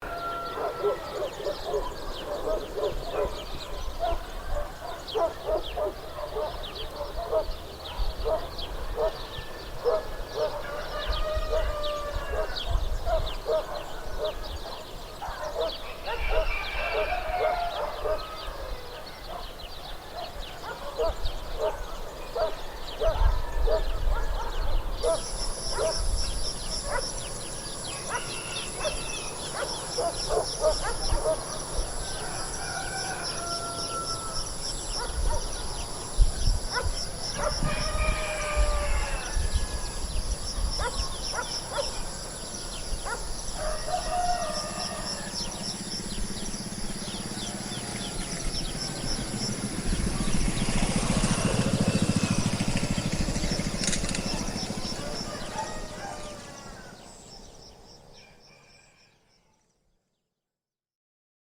Free Ambient sound effect: Village Ambiance With Animals.
Village Ambiance With Animals
Village Ambiance with Animals.mp3